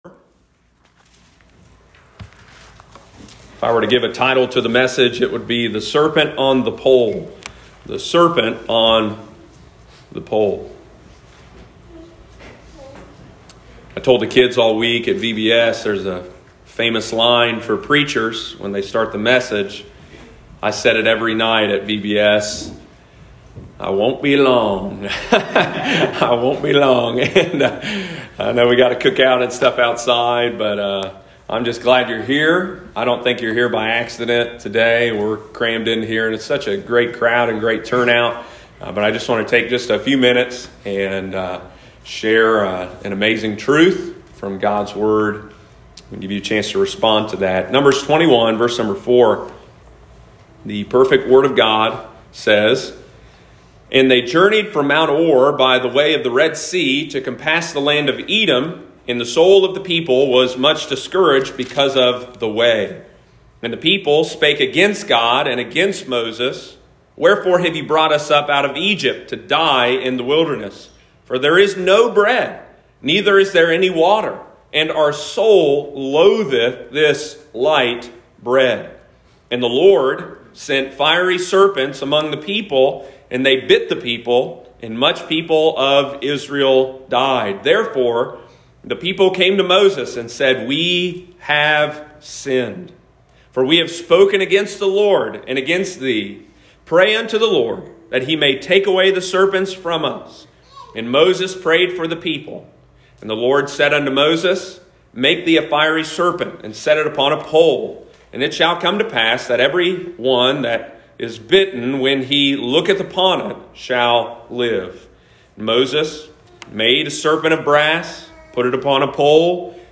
Community Fun Day, August 1, 2021.